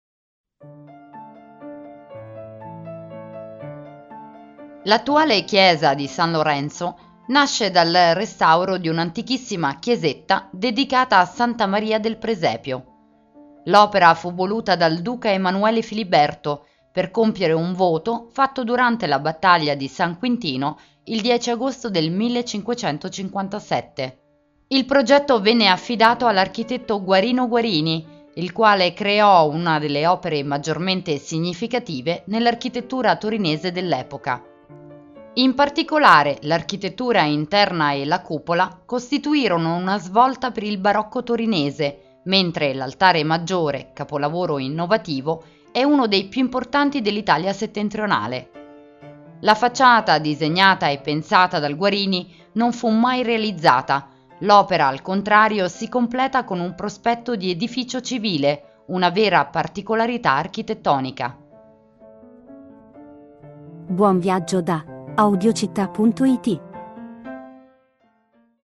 Audioguida Torino – Chiesa di San Lorenzo